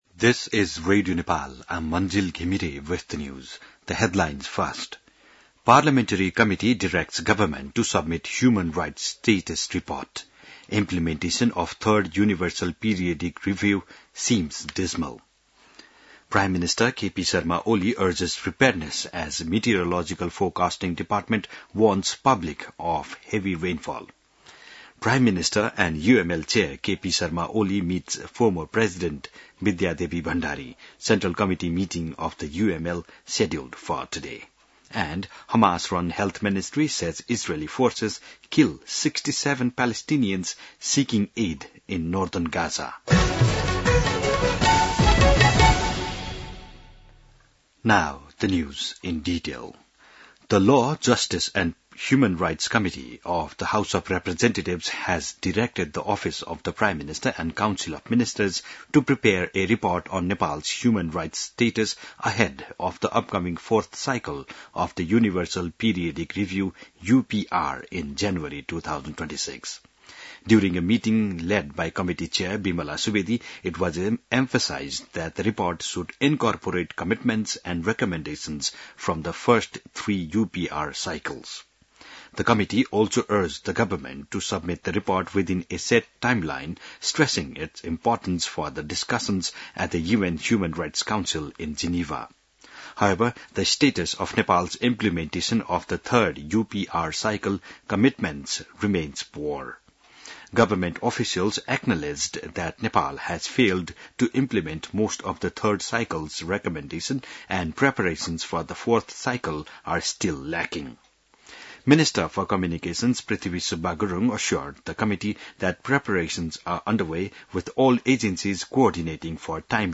बिहान ८ बजेको अङ्ग्रेजी समाचार : ५ साउन , २०८२